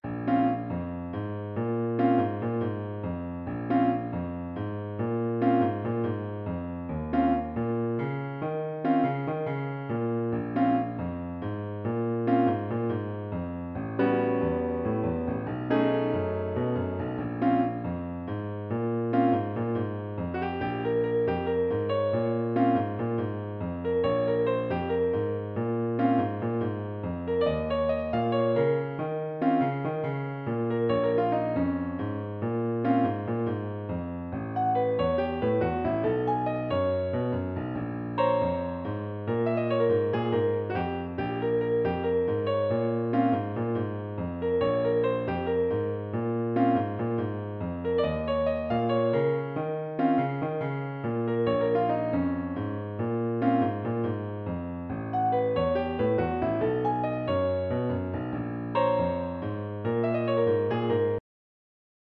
is clearly a Bb minor 12 bar blues